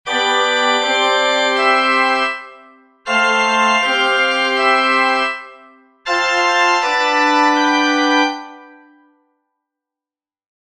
Auflösung nennt man die durch die spannende Wirkung einer Dissonanzbildung bedingte Fortschreitung einer oder mehrerer Stimmen.
Auflösung der Dissonanz